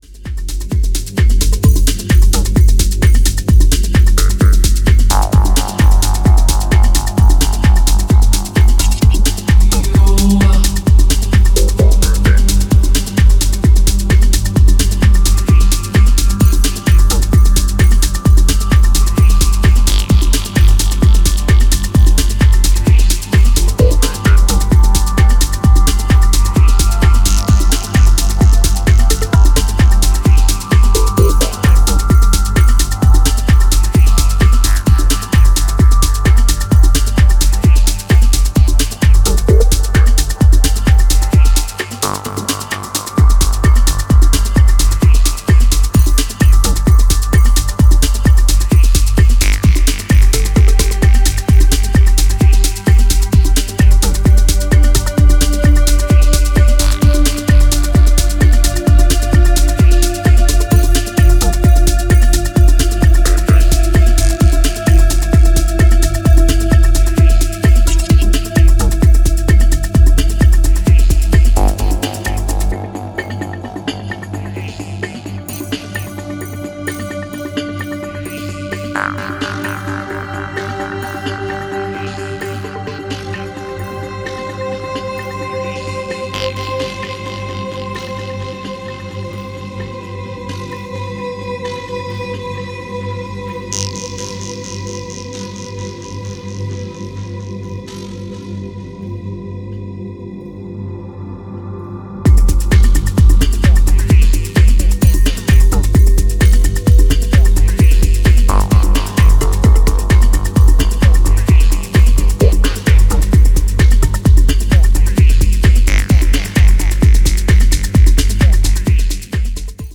シネマティックなストリングスパッドが格調高いムードを醸し出した